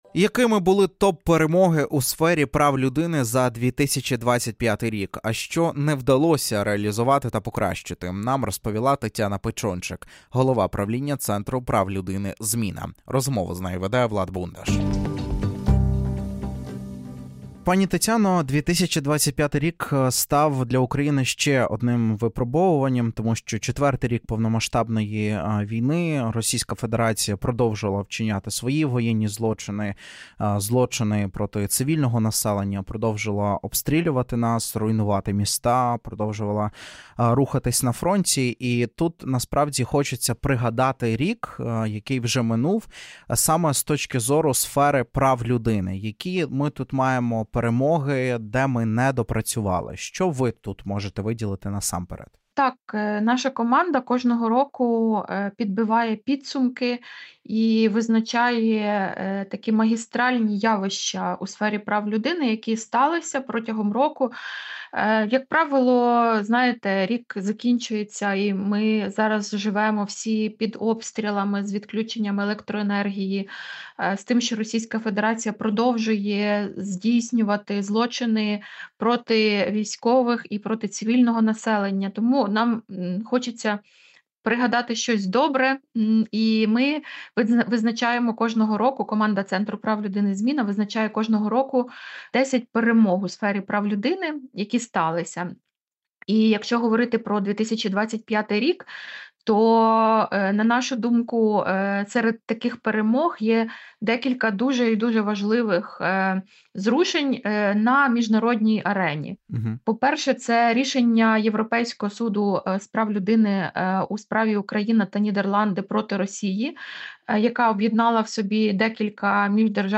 в етері "Громадського радіо"